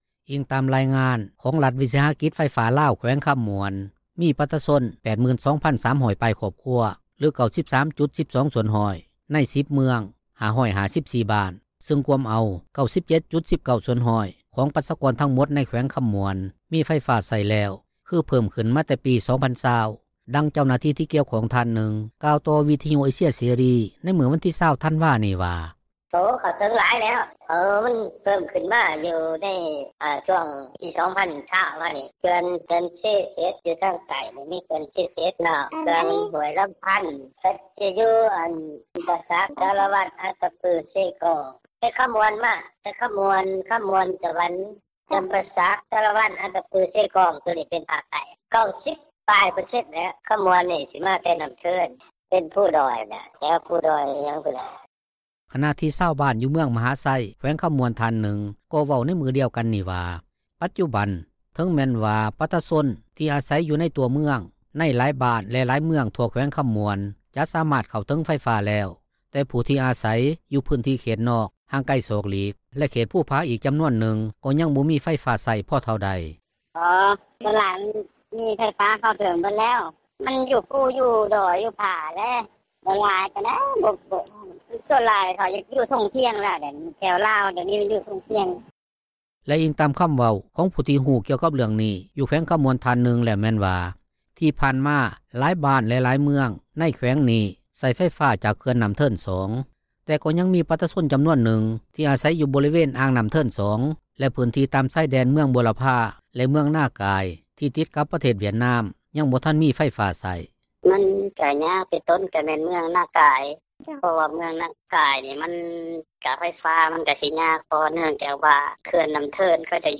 ດັ່ງເຈົ້າໜ້າທີ່ ທີ່ກ່ຽວຂ້ອງທ່ານນຶ່ງ ກ່າວຕໍ່ວິທຍຸເອເຊັຽເສຣີ ໃນມື້ວັນທີ 20 ທັນວານີ້ວ່າ:
ດັ່ງຊາວບ້ານ ຢູ່ເມືອງມະຫາໄຊຜູ້ນຶ່ງ ເວົ້າວ່າ: